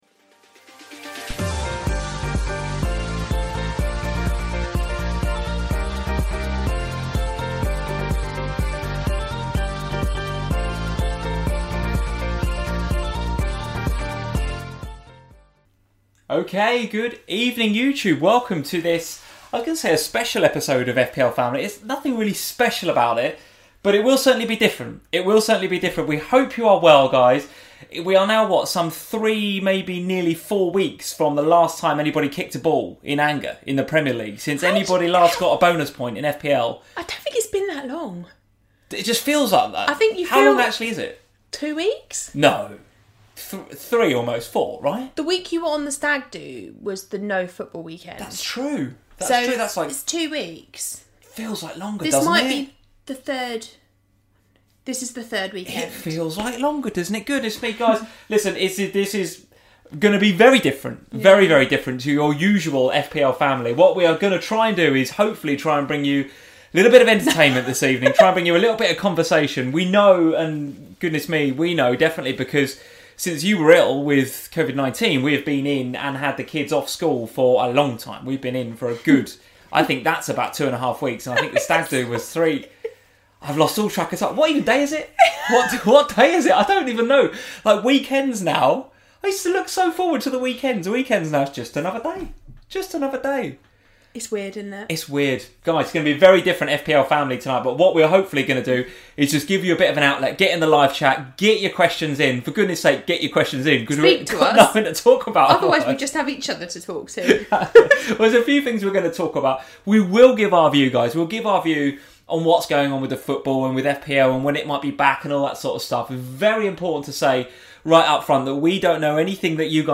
Welcome to FPL Family, a chat show dedicated to all things Fantasy Premier League.